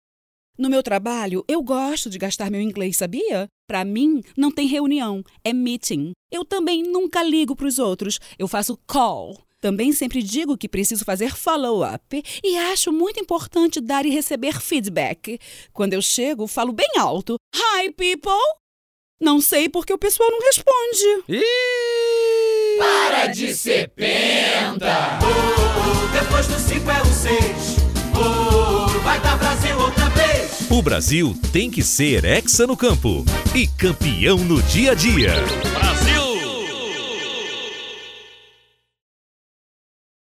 A mensagem é clara: “O Brasil tem que ser hexa no campo e campeão no dia a dia”, diz o locutor na assinatura dos spots de áudio, que vão ao ar a partir desta sexta-feira, dia 9, nas 35 emissoras da rede Rádio Globo.
A cada peça de áudio, um tipo diferente de “penta” se apresenta: um cidadão que joga lixo na rua e se irrita em saber que agora existe multa para reprimir esta sujeira; um motorista que usa celular ao volante, avança sinais e estaciona em cima de calçadas; uma pessoa que não larga o celular nem na hora de sentar com os amigos numa mesa de bar ou restaurante; e um morador de edifício que não cumprimenta os vizinhos no elevador, entre outros. Em todos os casos, ao final do texto, ouve-se o coro: “Para de ser penta!”.